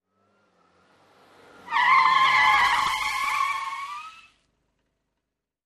Car, Skid, Quick In with Long Skid